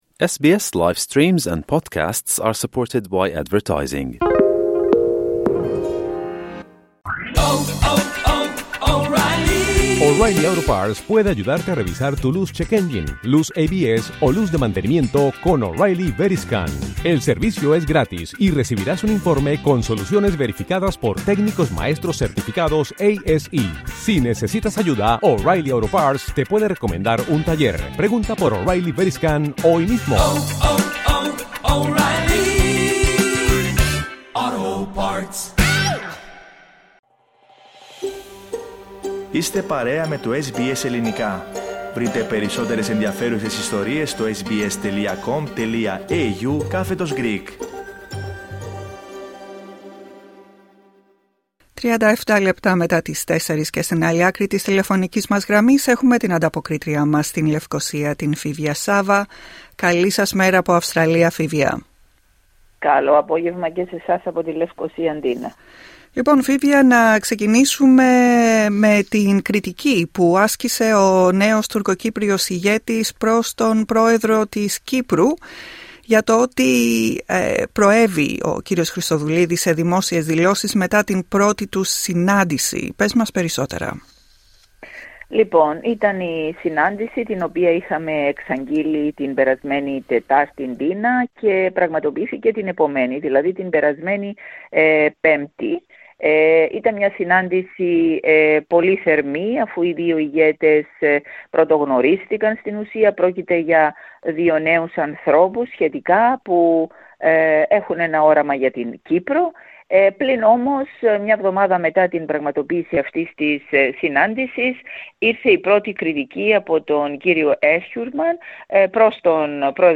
Ανταπόκριση-Kύπρος: Κριτική προς τον Κύπριο ΠτΔ εξαπολύει ο νέος Τουρκοκύπριος ηγέτης